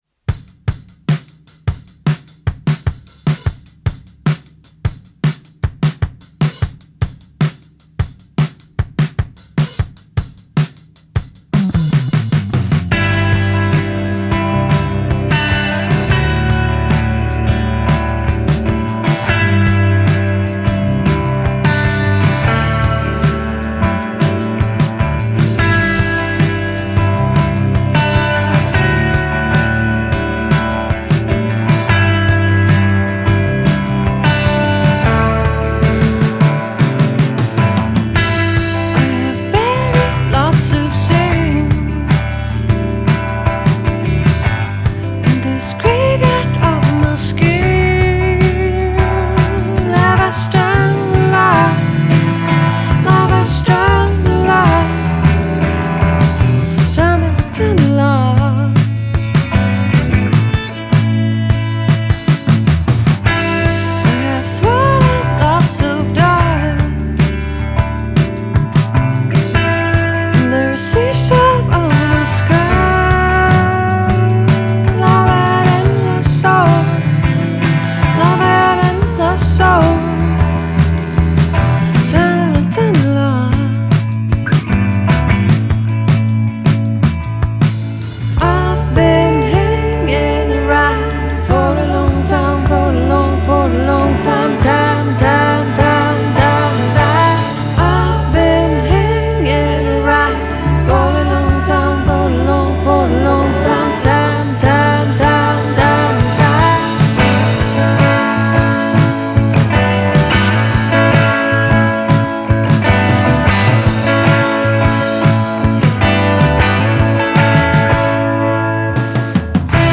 Indie from Poland tonight.